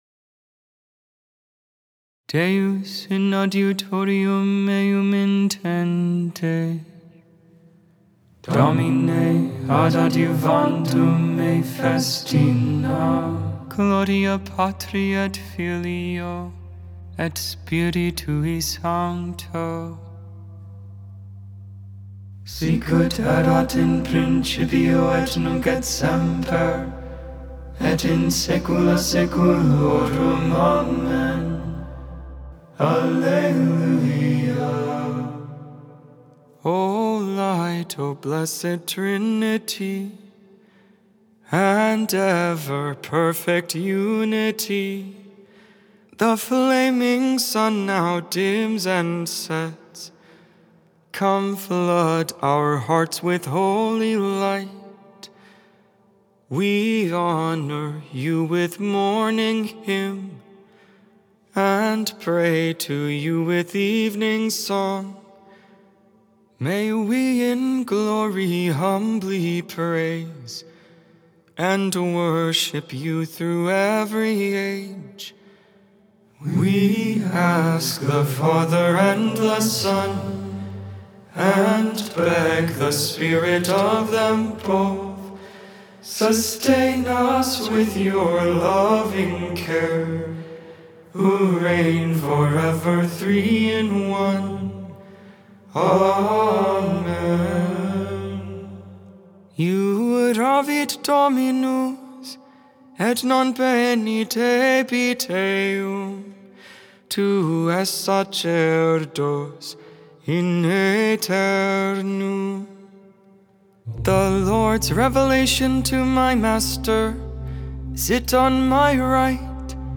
Learn the recurring chants of the Liturgy of the Hours!